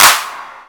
BOOMIN CLAP 3.wav